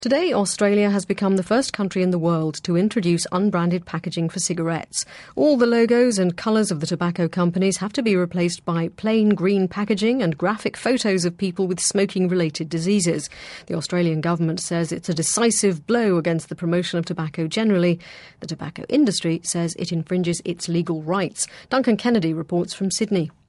【英音模仿秀】澳大利亚香烟包装将素颜出街 听力文件下载—在线英语听力室